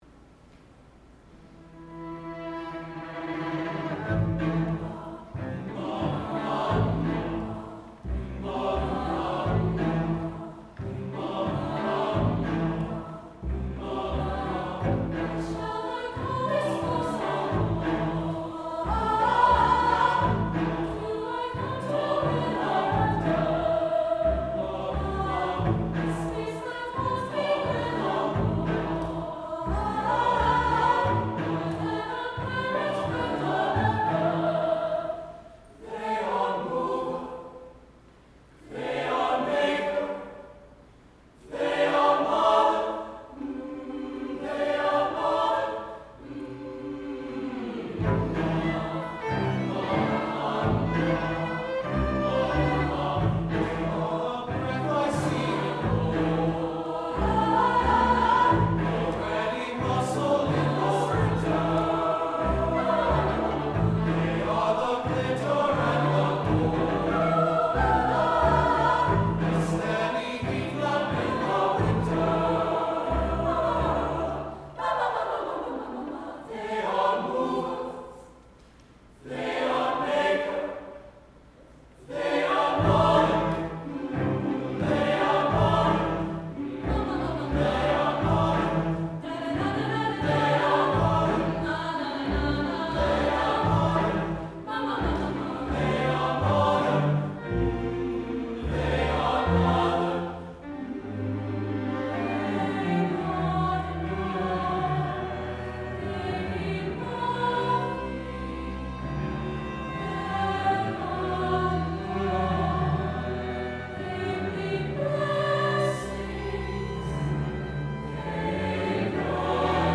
for SATB div. choir, SA solo group, and string quintet